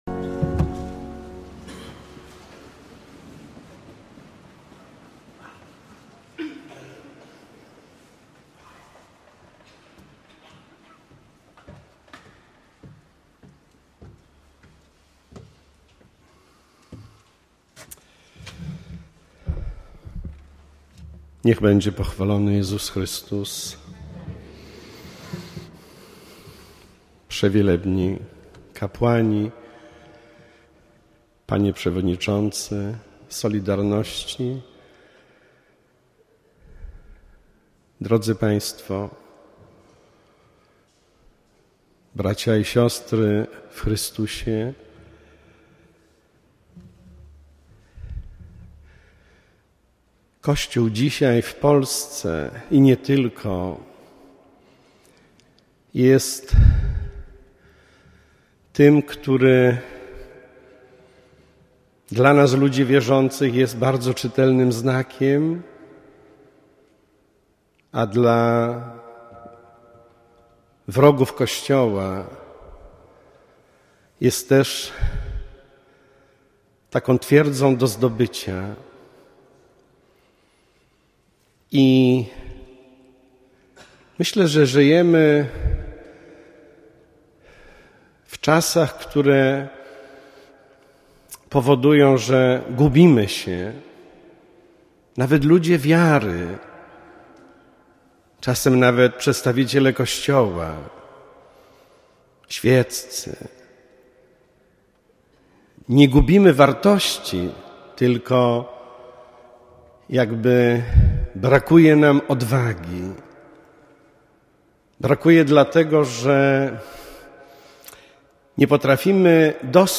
kazanie.mp3